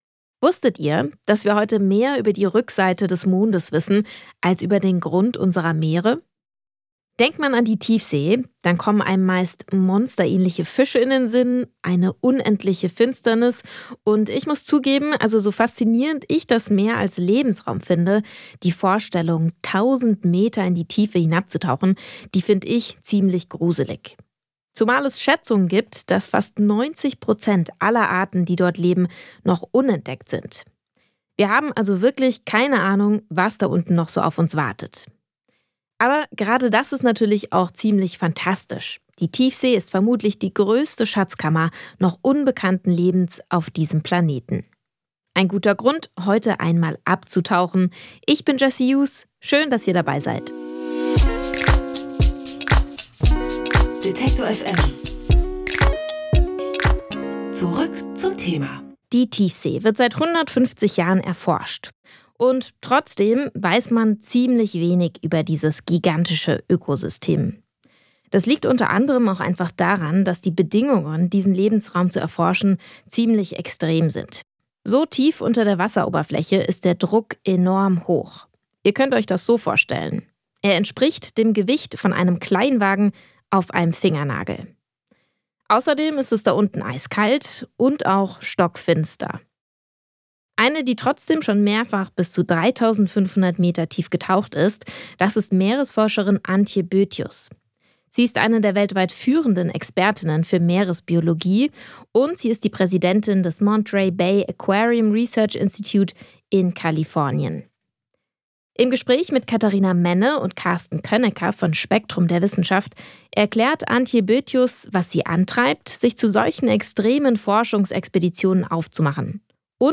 Meeresbiologin Antje Boetius im Gespräch über die Herausforderungen und die Faszination der Tiefseeforschung.